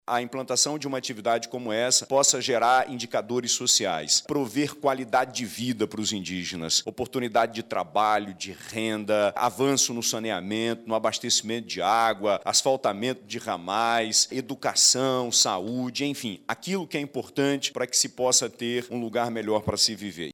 De acordo com o governador do Amazonas, Wilson Lima, a exploração do potássio, em Autazes, vai trazer desenvolvimento econômico e social para o Estado.